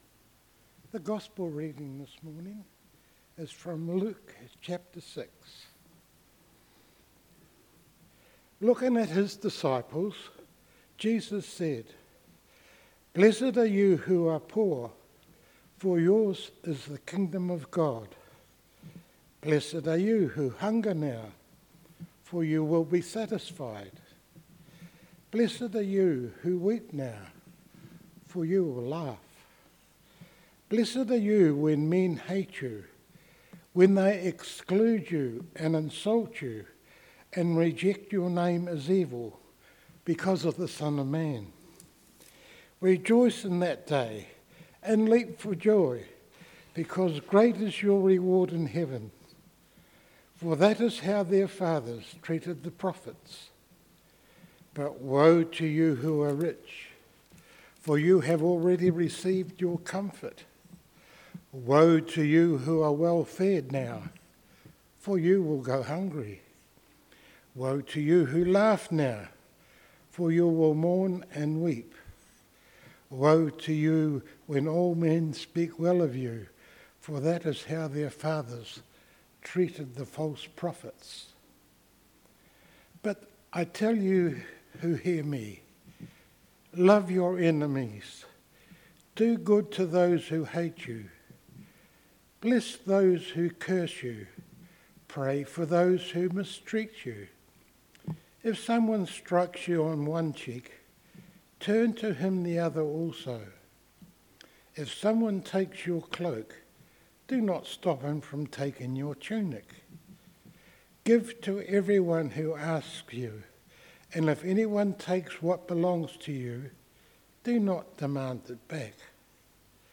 A sermon for All Saints Day talking about Wesleyan missionary James Watkin.